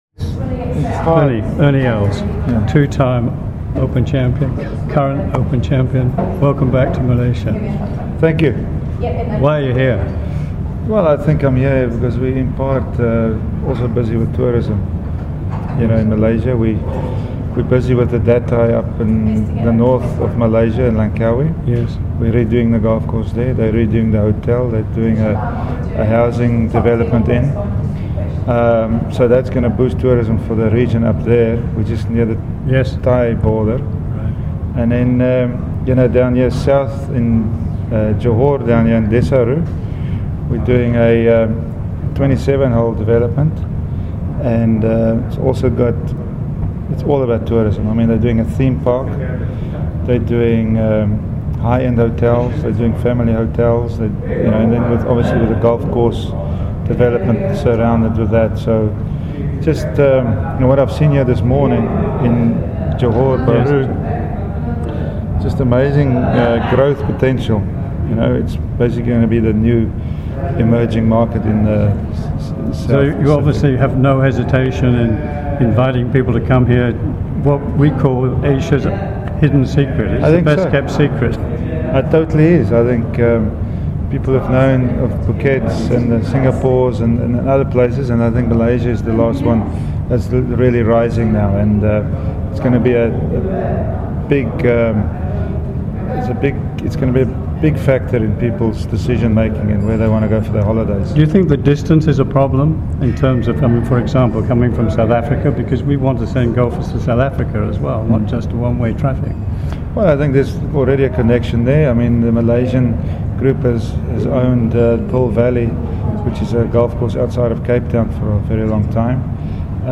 MGTA interviews Ernie Els